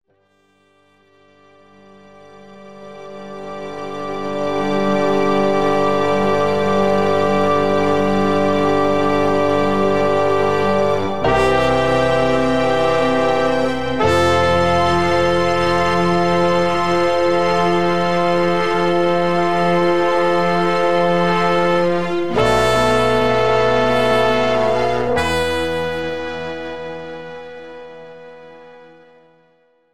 This is an instrumental backing track cover.
• Key – G, F
• Without Backing Vocals
• No Fade